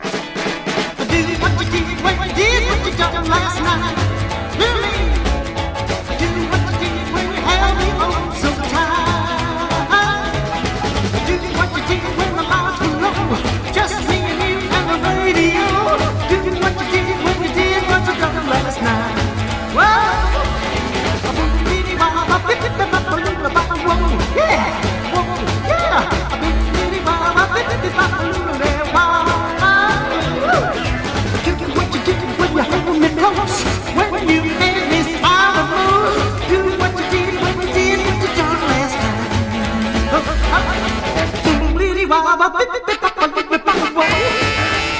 真っ白なウッドベースがトレードマークで、ここでは加えてギターとドブロ、スティールギターを演奏。
Rock'N'Roll, Rockabilly　Finland　12inchレコード　33rpm　Stereo